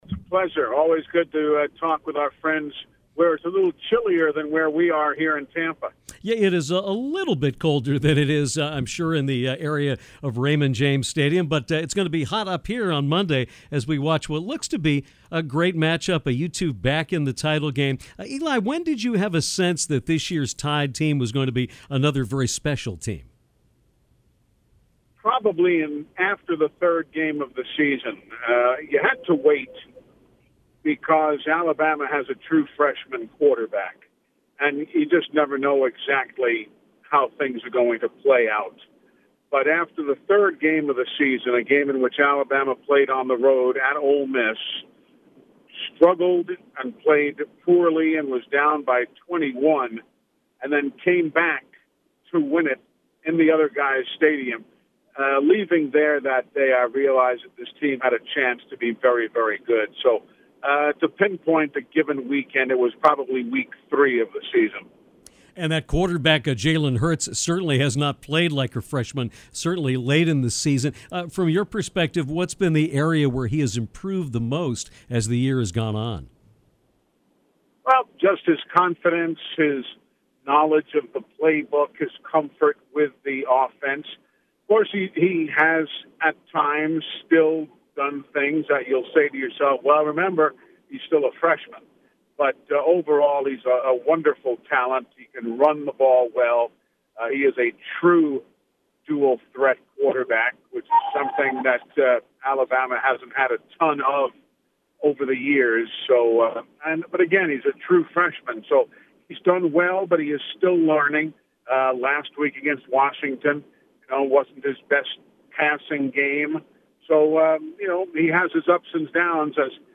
Alabama football broadcaster Eli Gold
The voice of the Crimson Tide, Eli Gold, returned to the Downtown airwaves to help preview Monday night’s national championship game of college football between Alabama and Clemson. Eli shared his thoughts on the match-up and also told us about a different side of Coach Nick Saban, that of the loving grandfather, who also is a huge fan of The Eagles.